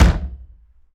Impact.wav